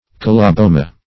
Search Result for " coloboma" : The Collaborative International Dictionary of English v.0.48: Coloboma \Col`o*bo"ma\, n. [NL. fr. Gr.